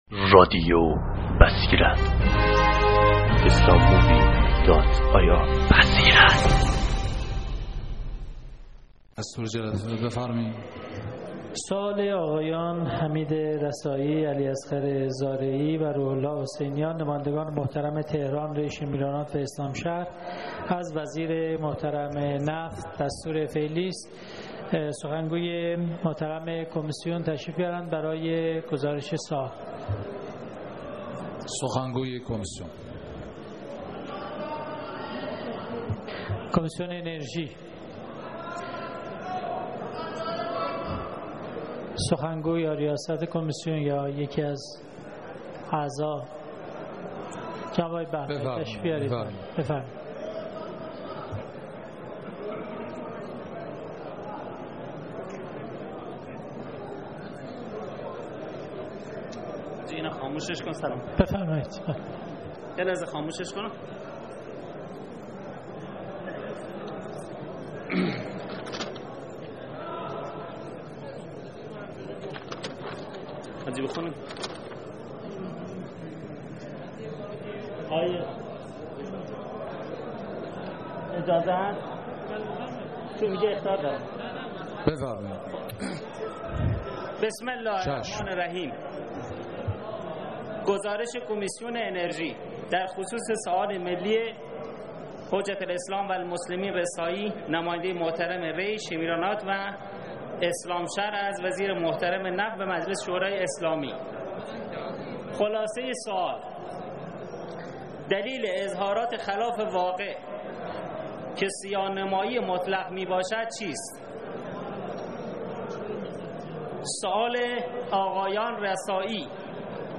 دانلود صوت سوال رسایی از وزیر نفت در صحن علنی مجلس – 26 فروردین 93 – مجله نودیها
سخنرانی سیاسی